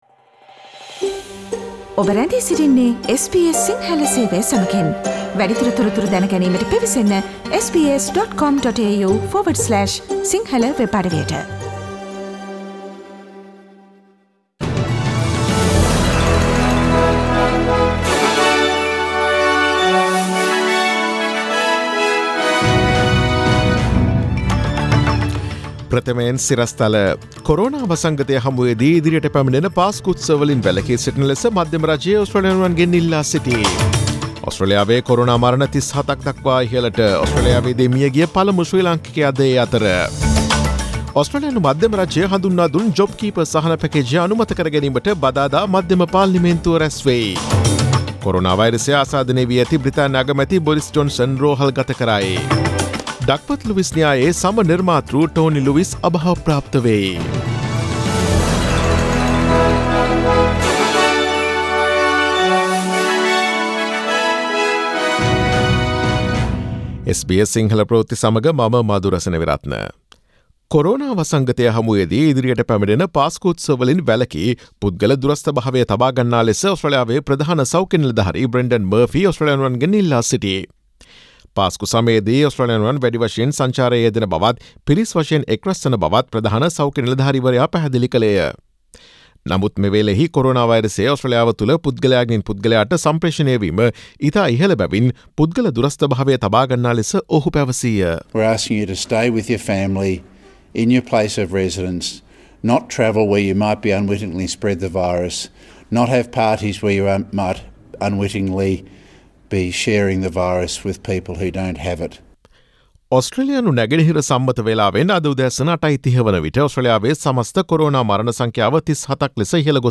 Daily News bulletin of SBS Sinhala Service: Monday 06 April 2020
Today’s news bulletin of SBS Sinhala Radio – Monday 06 April 2020 Listen to SBS Sinhala Radio on Monday, Tuesday, Thursday and Friday between 11 am to 12 noon